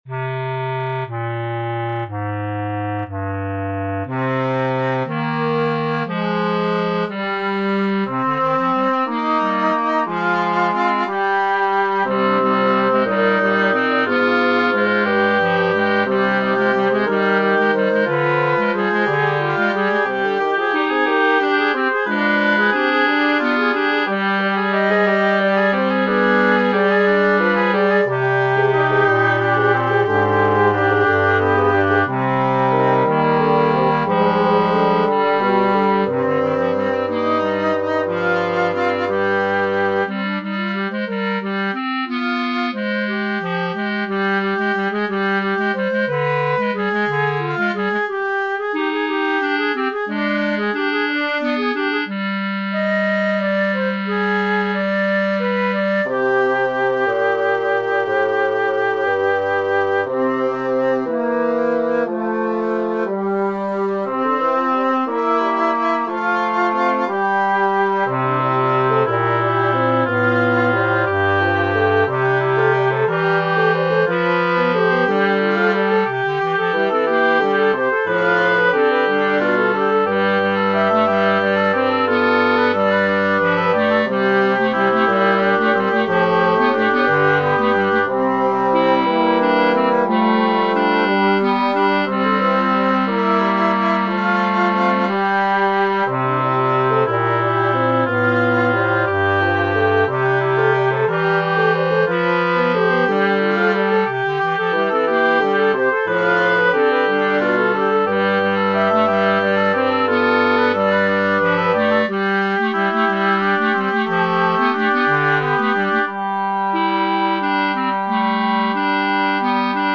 klarinetgeluid).
Zutphen Kanon-klarinet geluid.mp3